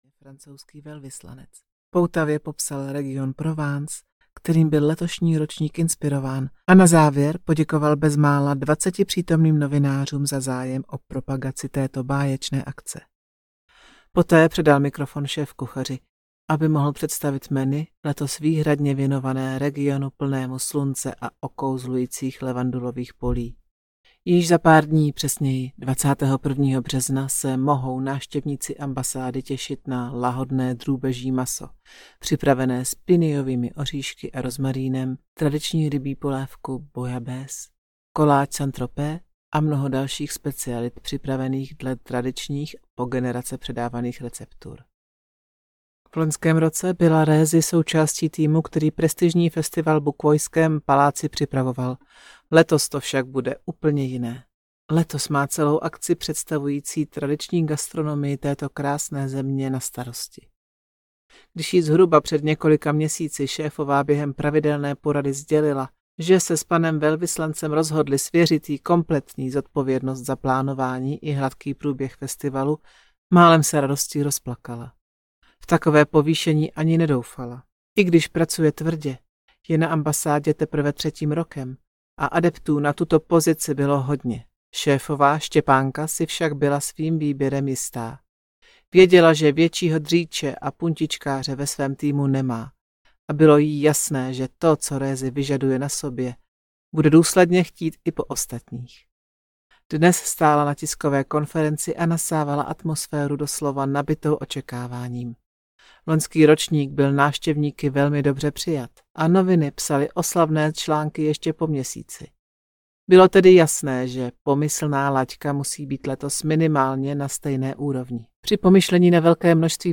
Ukázka z knihy
davna-moc-zlateho-kapradi-audiokniha